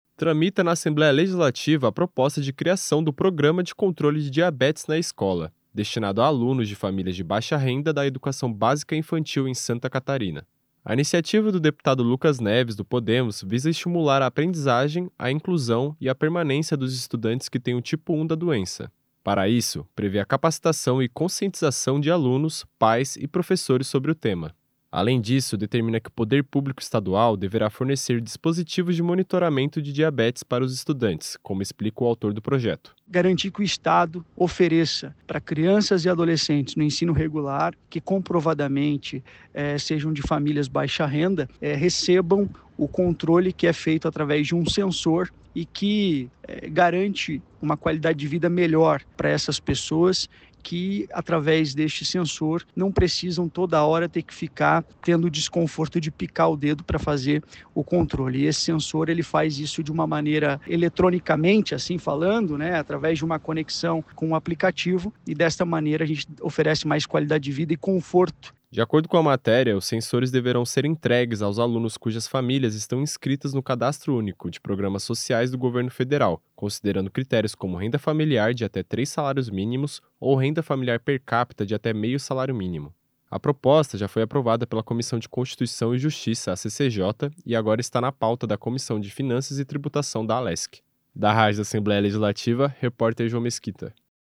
Entrevista com:
- deputado Lucas Neves (Podemos), autor da proposta.